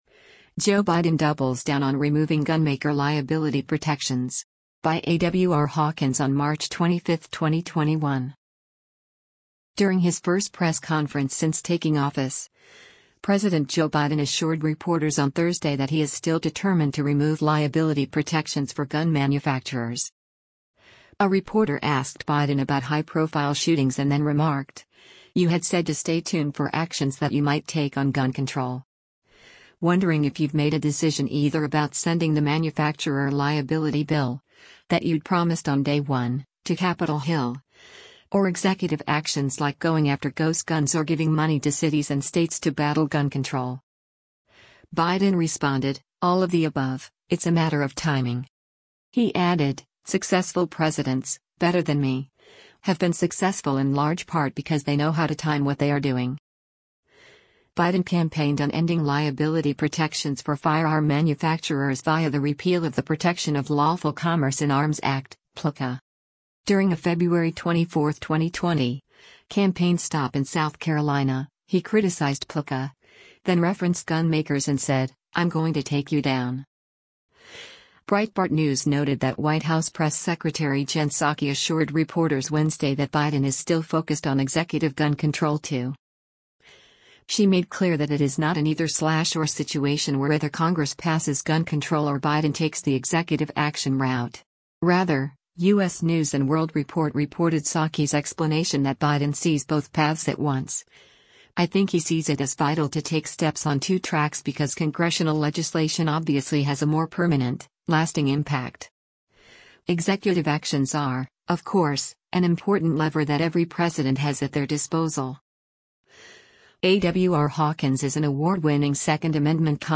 During his first press conference since taking office, President Joe Biden assured reporters on Thursday that he is still determined to remove liability protections for gun manufacturers.